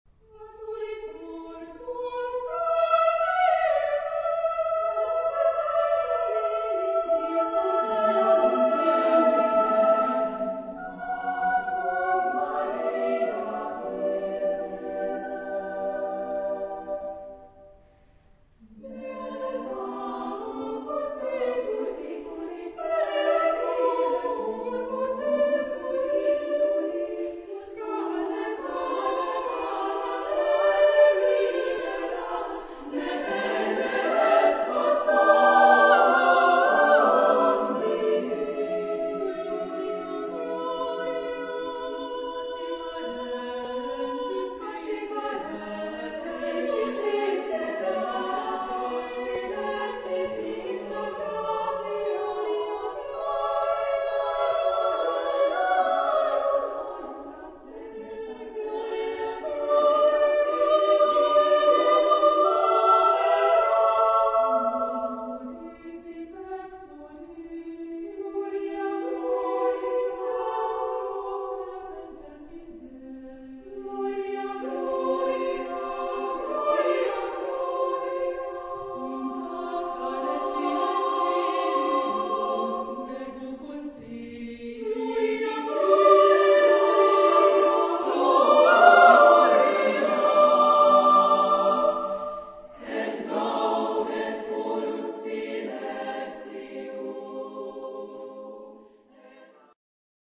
Género/Estilo/Forma: Motete ; contemporáneo ; Sagrado
Tonalidad : do mayor